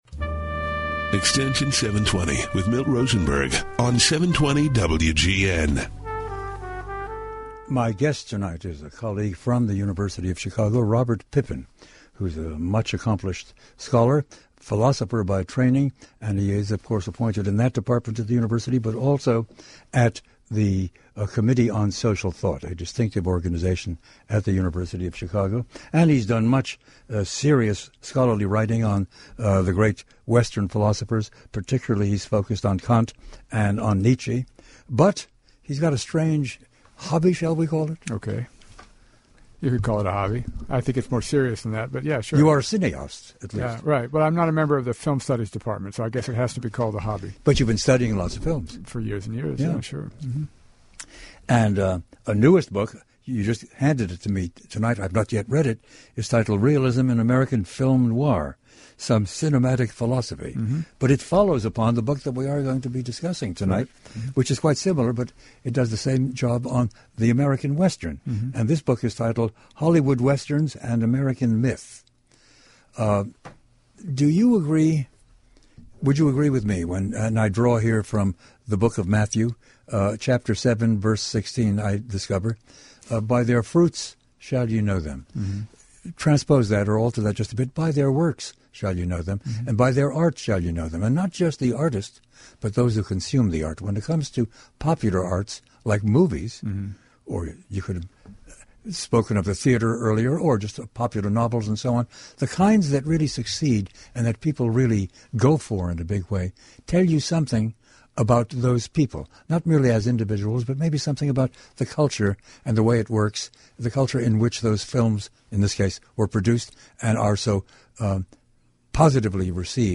We’re joined by Robert Pippin, author of Hollywood Westerns and American Myth, who uses audio examples from classic Westerns to show how they philosophize American life.